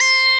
bone c6.wav